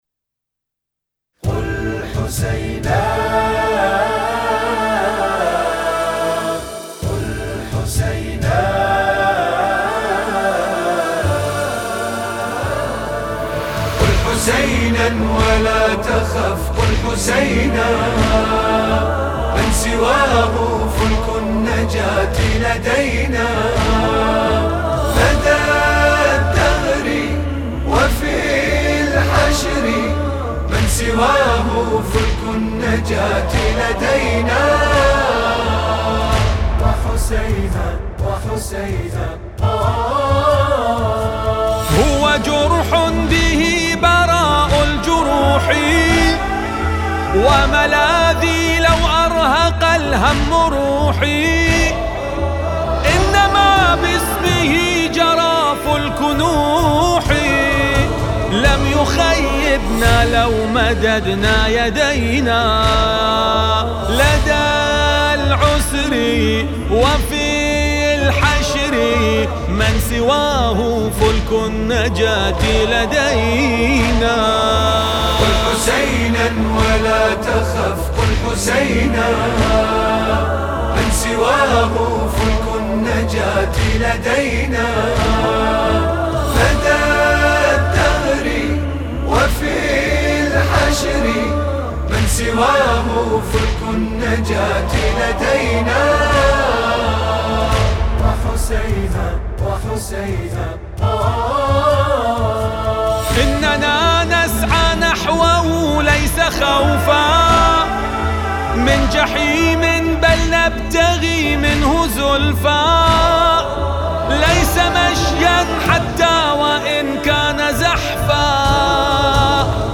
لطميات المحرم